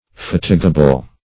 Search Result for " fatigable" : The Collaborative International Dictionary of English v.0.48: Fatigable \Fat"i*ga*ble\, a. [L. fatigabilis: cf. F. fatigable.